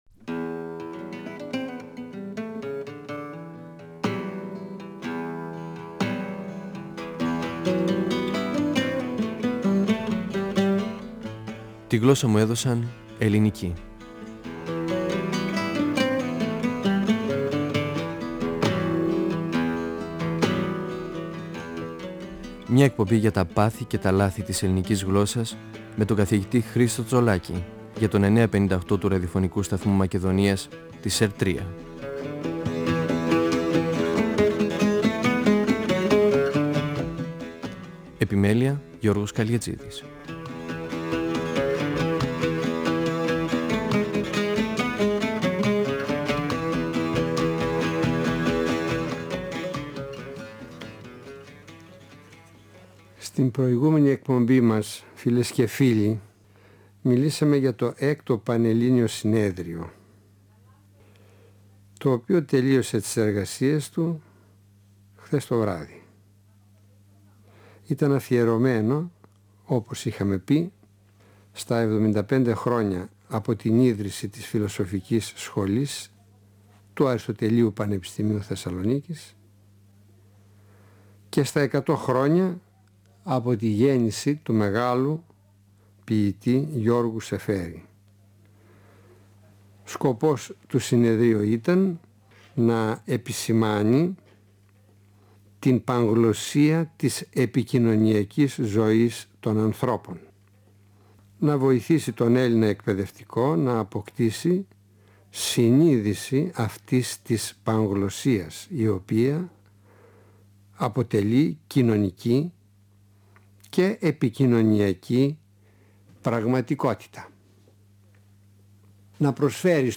Αναφέρεται στον ρόλο της Παιδείας σύμφωνα με τον Αλέξανδρο Δελμούζο και διαβάζει την αντιφώνηση του Γιώργου Σεφέρη κατά την τελετή βράβευσής του με το Νόμπελ Λογοτεχνίας (1963).
Νησίδες & 9.58fm, 1999 (πρώτος, δεύτερος, τρίτος τόμος), 2006 (τέταρτος τόμος, πέμπτος τόμος). 958FM Αρχειο Φωνες Τη γλωσσα μου εδωσαν ελληνικη "Φωνές" από το Ραδιοφωνικό Αρχείο Εκπομπές ΕΡΤ3